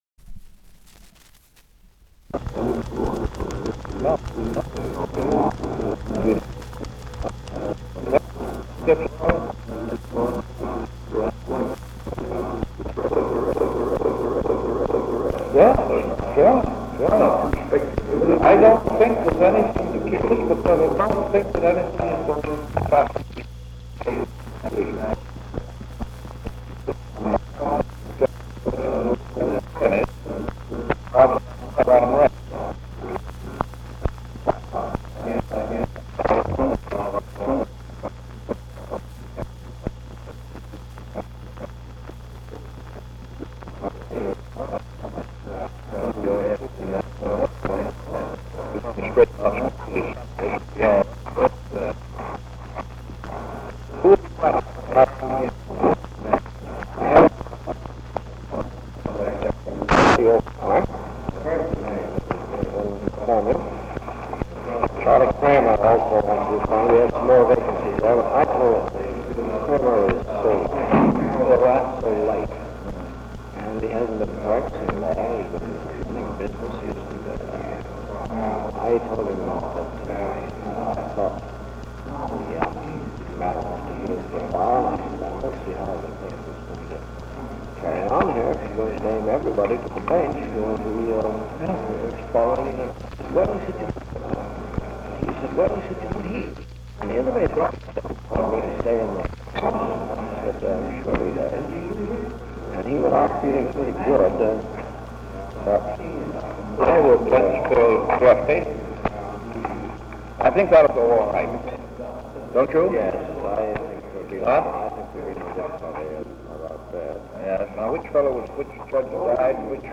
Office Conversation
Secret White House Tapes | Franklin D. Roosevelt Presidency